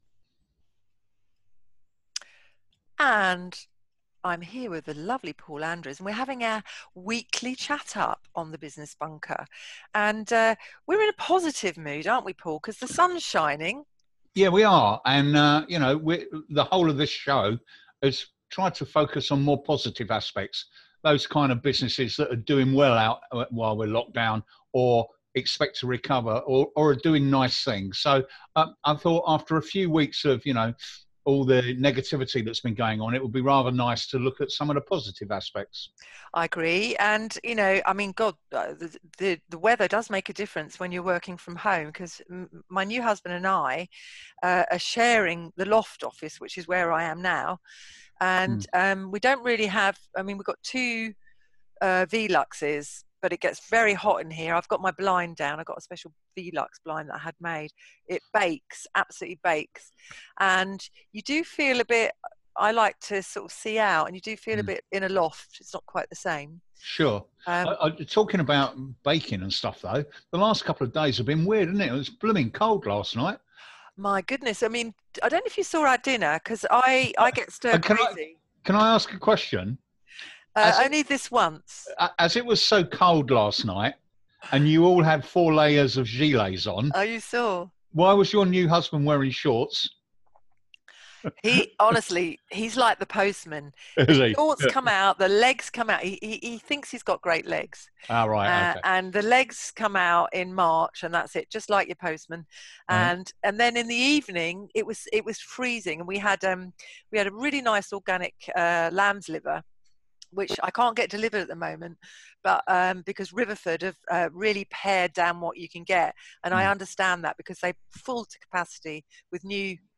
met up via Zoom during week 8 of UK lockdown due to Covid19 Pandemic